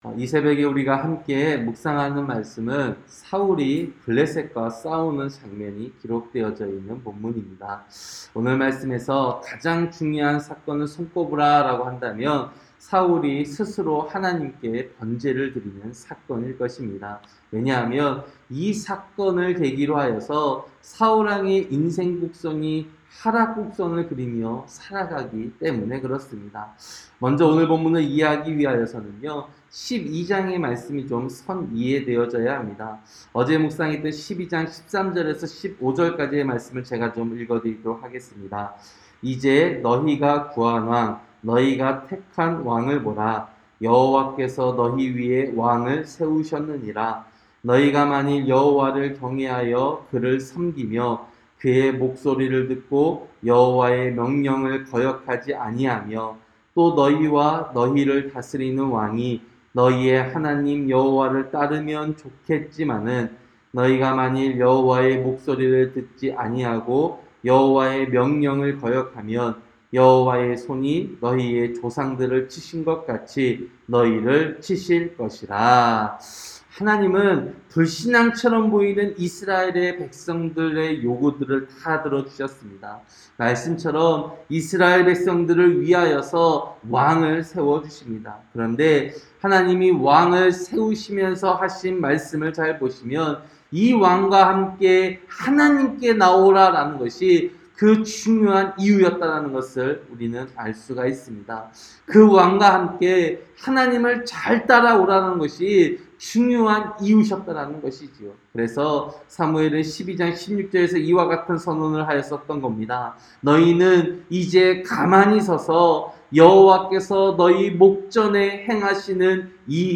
새벽설교-사무엘상 13장